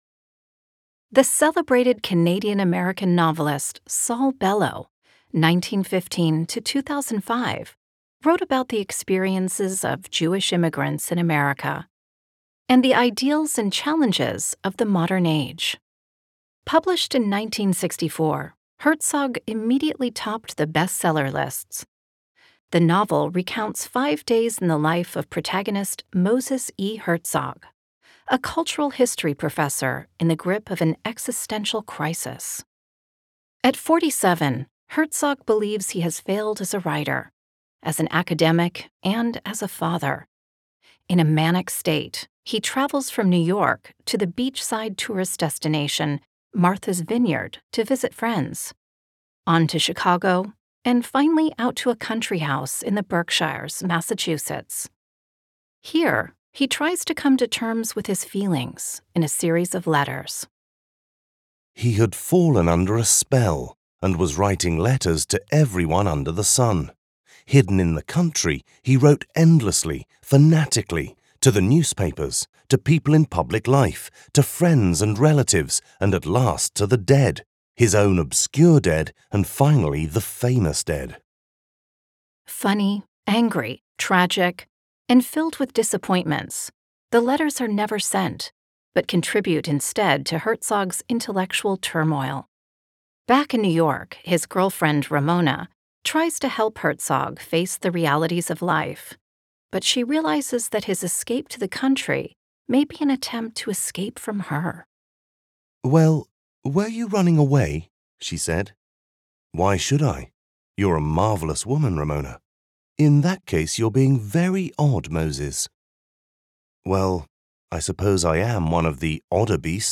Speaker (UK accent)
Speaker (American accent)